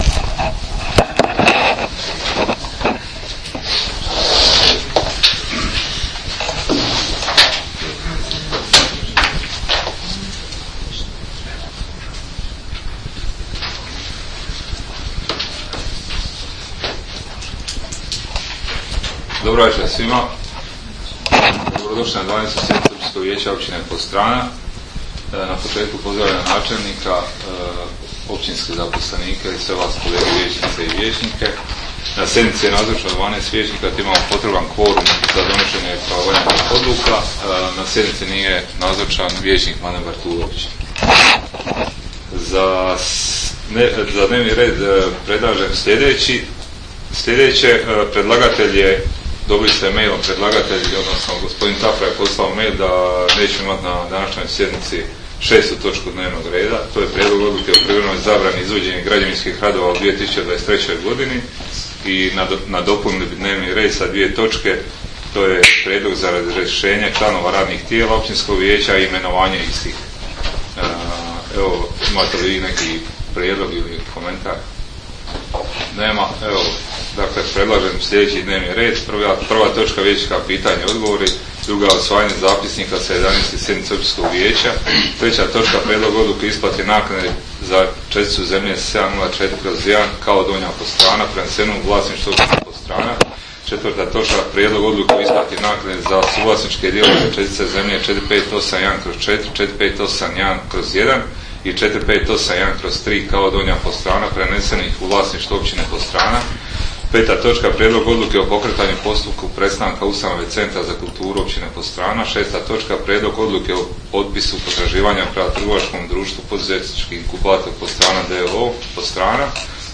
Sjednica će se održati dana 10. studenoga (četvrtak) 2022. godine u 19,00 sati u vijećnici Općine Podstrana.